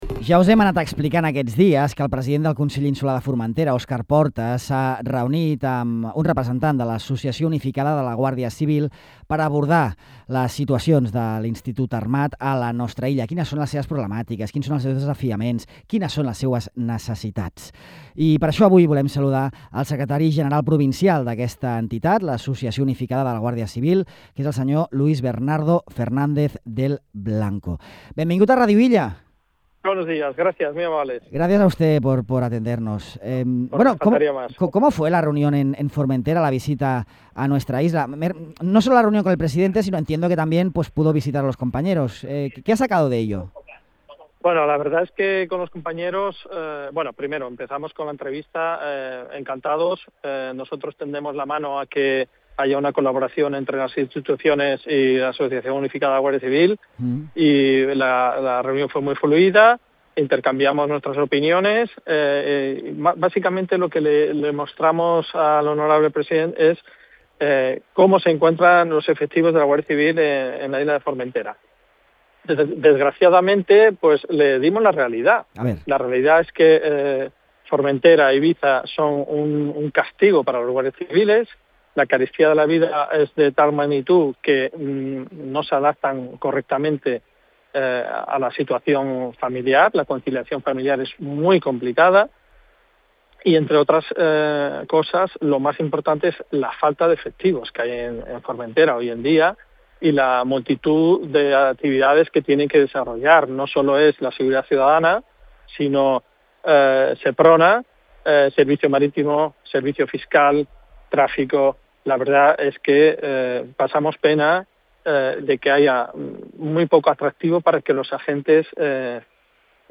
En una entrevista a Ràdio Illa, ha dibuixat un panorama preocupant: manca de personal, plus d’insularitat insuficient, problemes de conciliació familiar i dificultats greus per trobar habitatge.